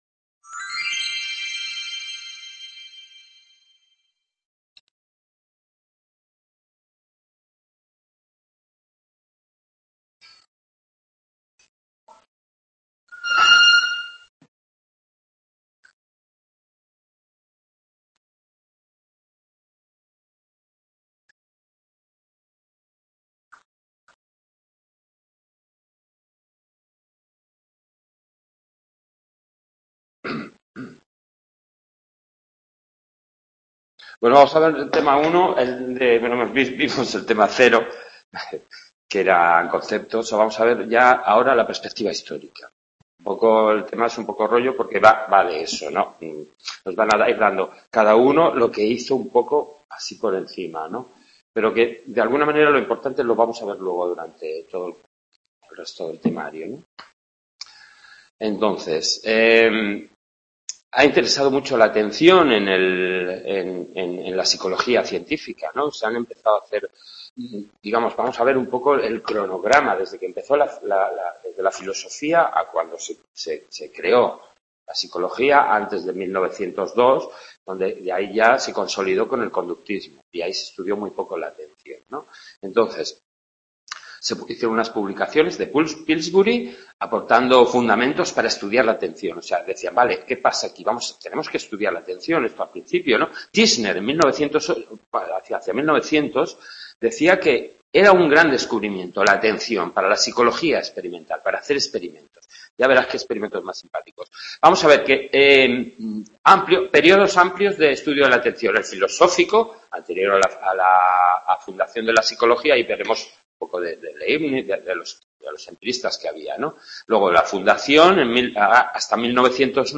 Clase de Psicología de la Atención, tema 1, grabada en el aula de Sant Boi